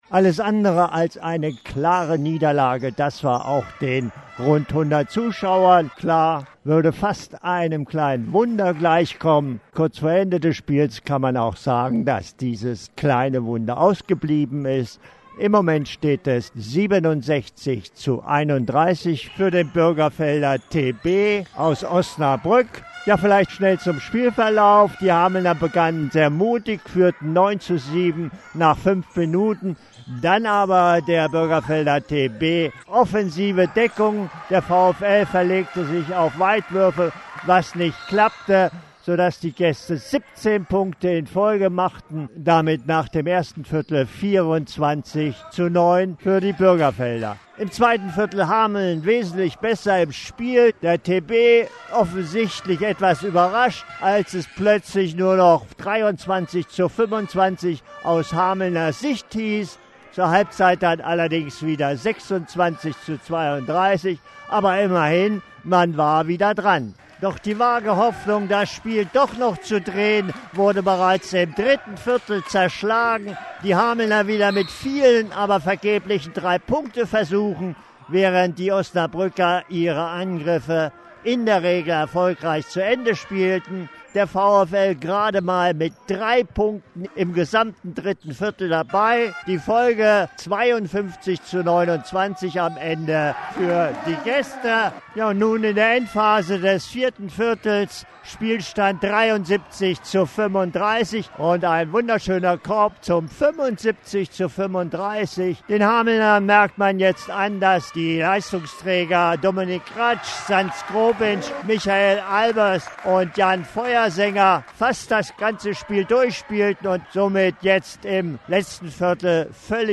Reportage vom Spiel gegen Bürgerfelder TB